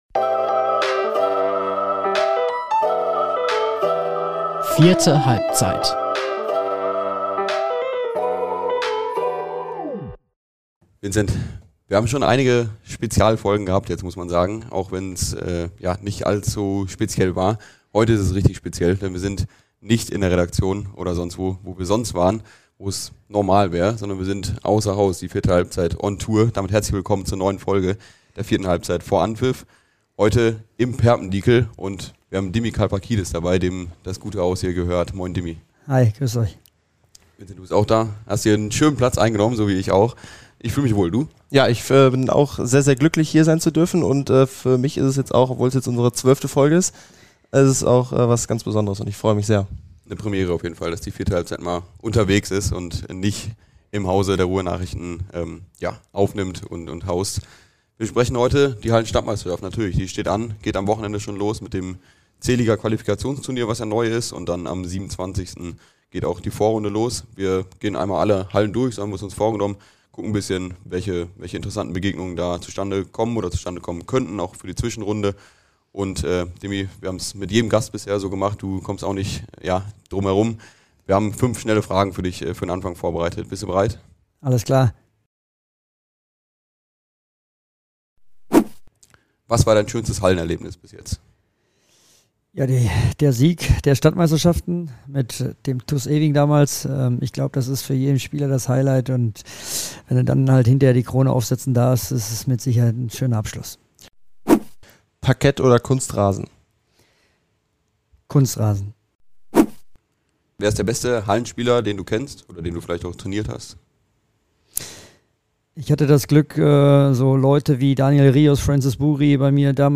Denn der Podcast Vierte Halbzeit vor Anpfiff grüßt von einem besonderen Ort.